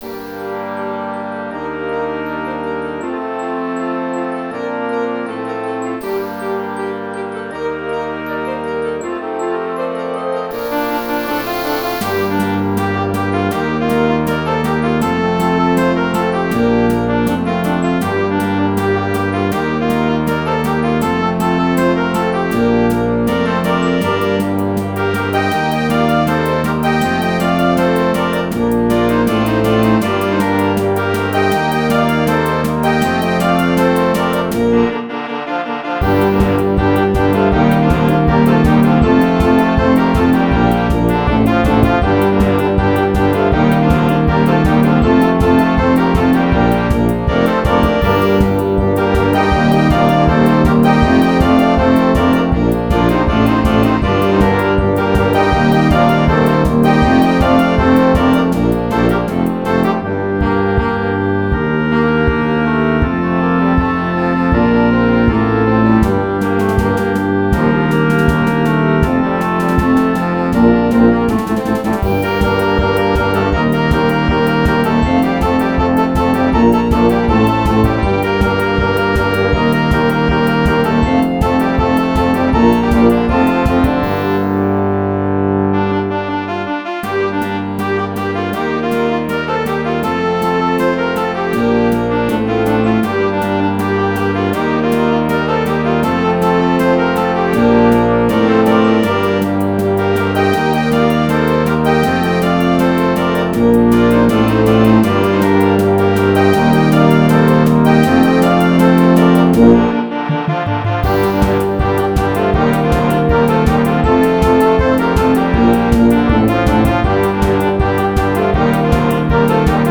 erstmals für Blasmusik spielbar.